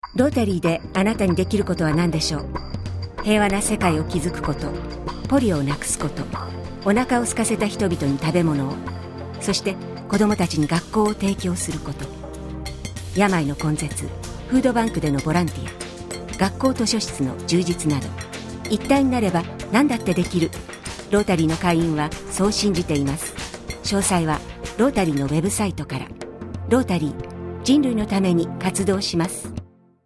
「人類のために活動します」のラジオ用公共奉仕広告（その2）